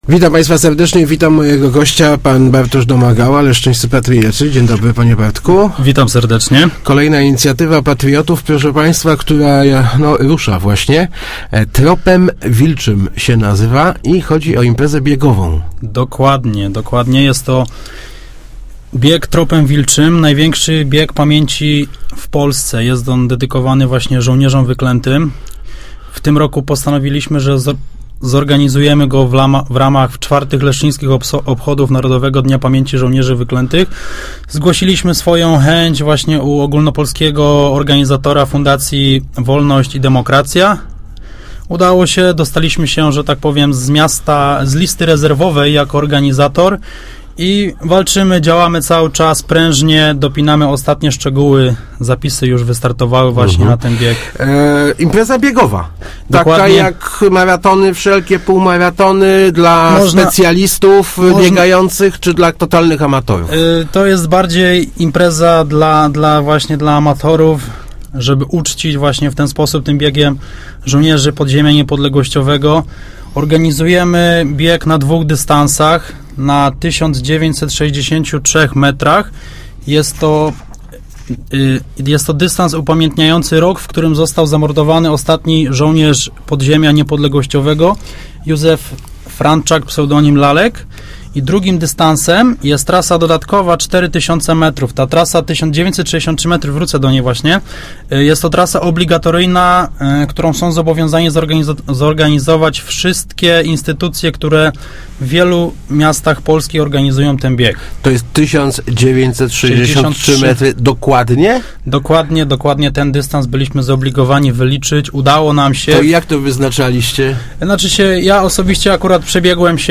Start arrow Rozmowy Elki arrow Patriotyczne bieganie